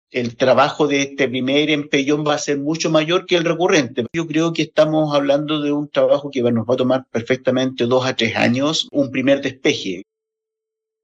Entre dos y tres años podría demorar el primer retiro de cables en desuso de los tendidos eléctricos en Chile, según afirmó el subsecretario de Telecomunicaciones, Claudio Araya, en conversación con el Expreso Bío Bío.